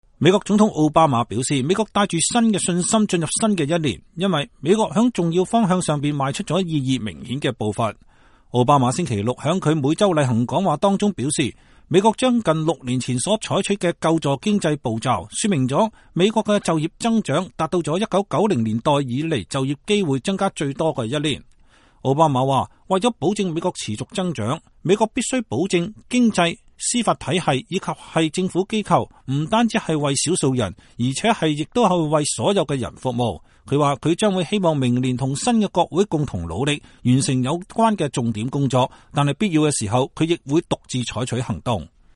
奧巴馬星期六在他每週例行廣播講話中說，美國將近六年前所採取的救助經濟的步驟，説明了美國的就業增長，達到了1990年代以來就業機會增加最多的一年。